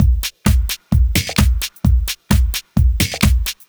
Minimal Funk 01.wav